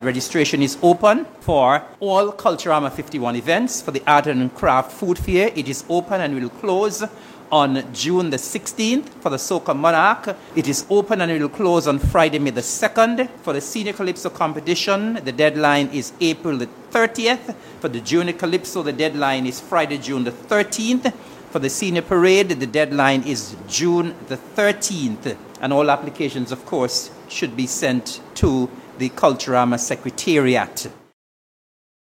Deputy Premier, Hon. Eric Evelyn.